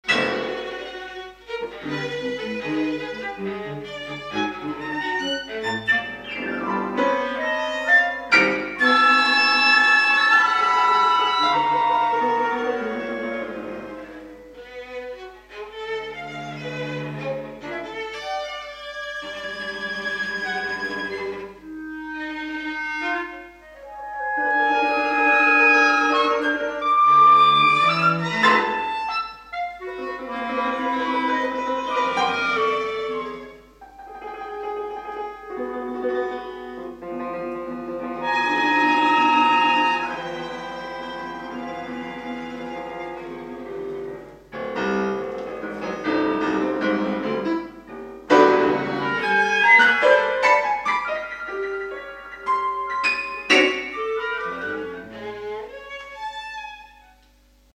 (2000) concerto dal vivo (estratto)
audio 44kz stereo